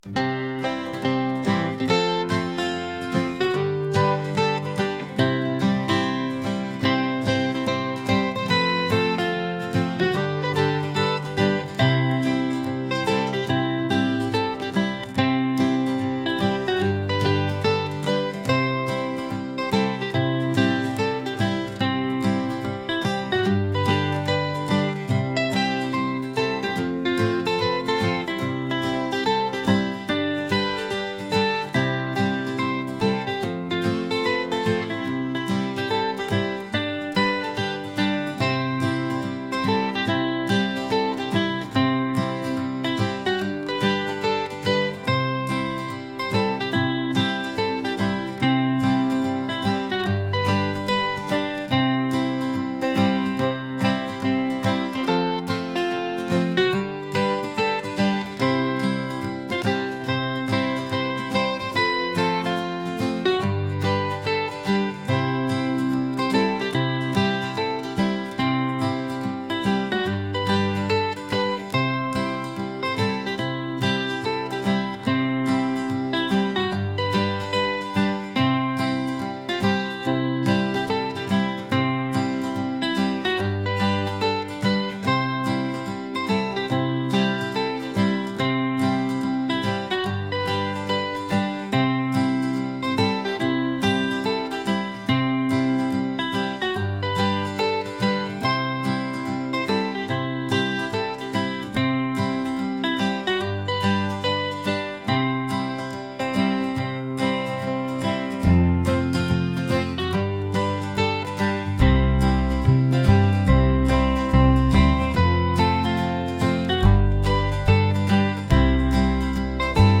folk | energetic